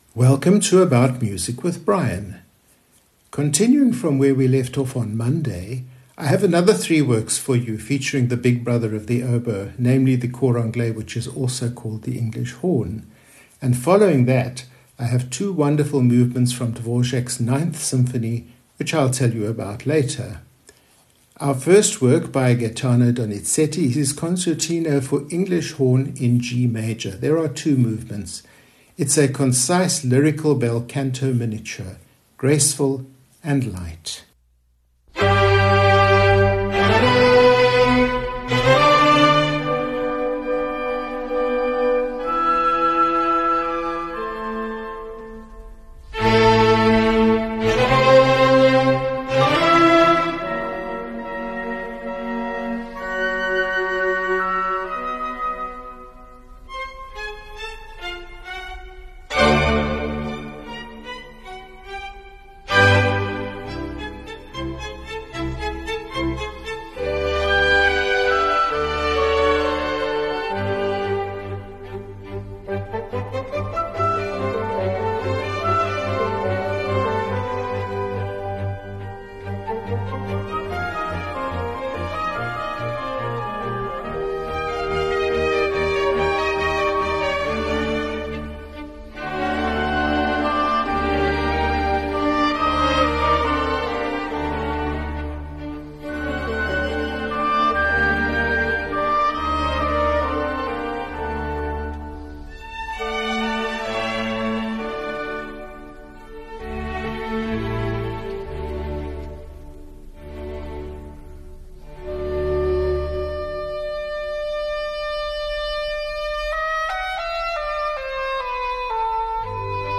This is a light, listener-friendly programme — Classical concertos, a lyrical modern gem, and the familiar warmth of Dvořák.